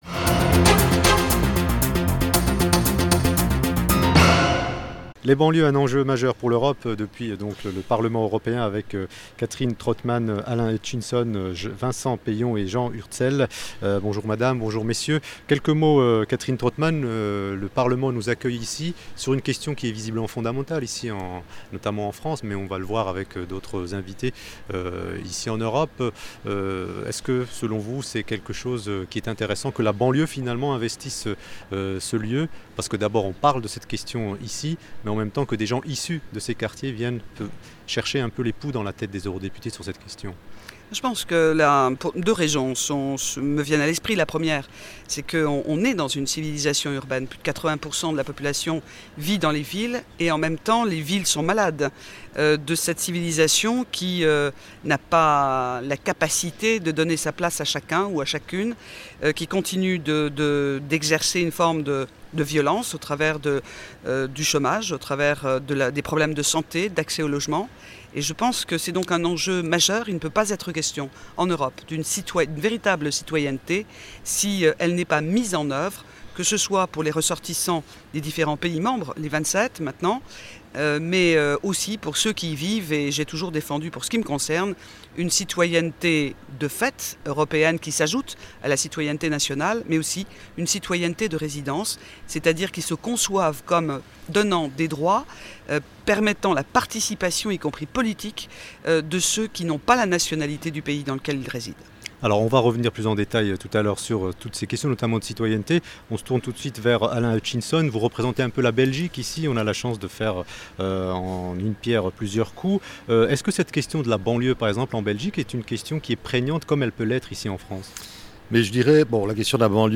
Studio RADIO mobile « parlement Strasbourg » le 12 décembre 2006. 14h-17h.